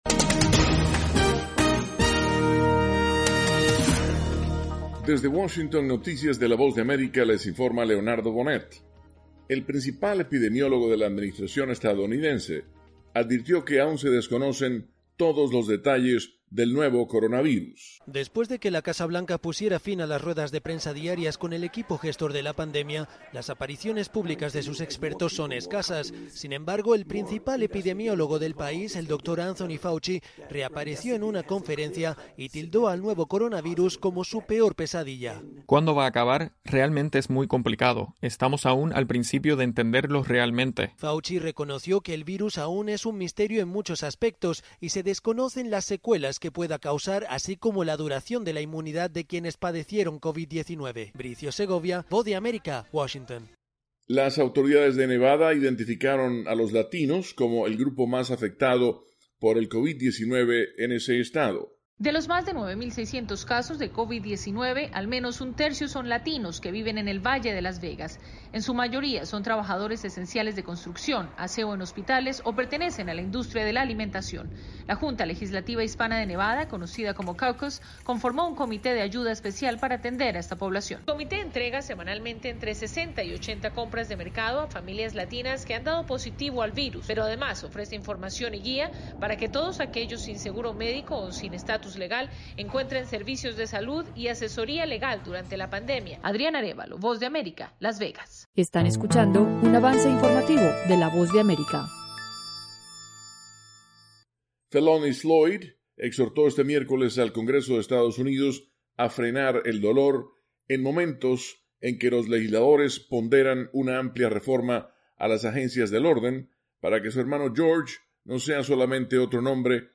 Segmento informativo de 3 minutos con noticias de Estados Unidos y el mundo.